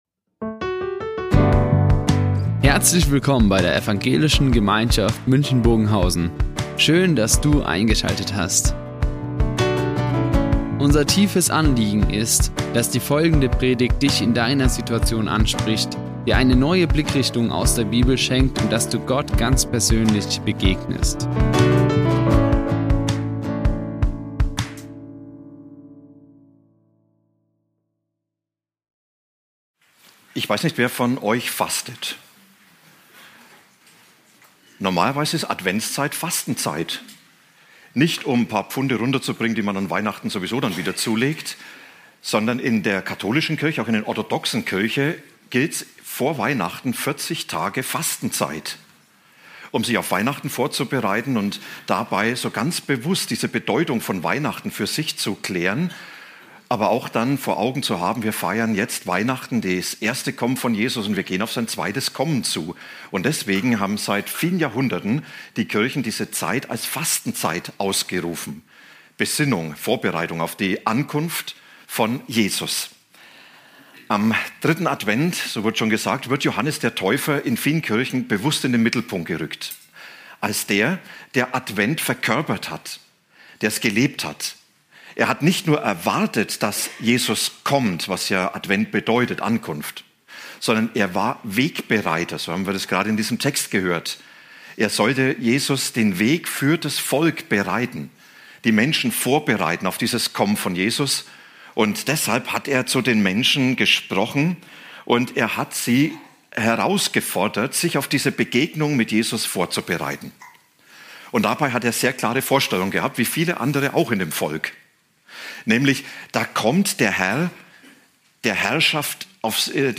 Advent - Zeit für Neues | Predigt Lukas 3,1-20 i.A. ~ Ev.
Die Aufzeichnung erfolgte im Rahmen eines Livestreams.